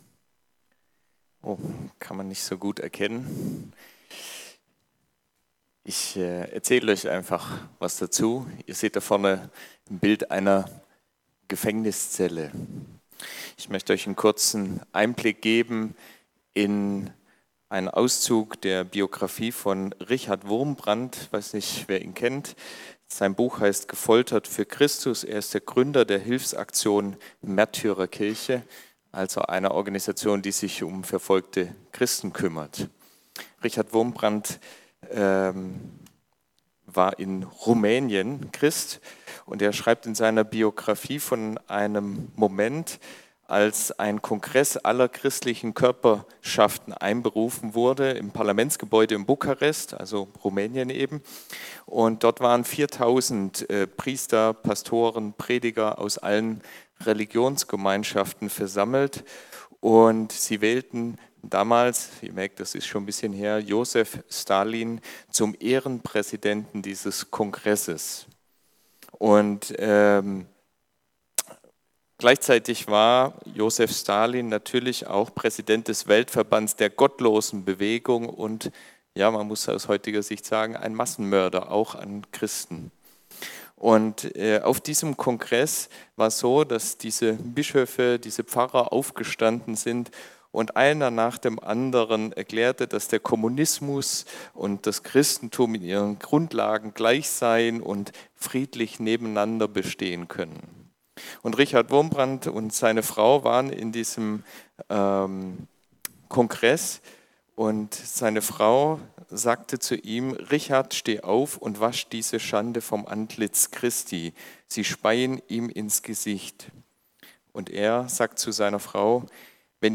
Predigtdetails anzeigen und abspielen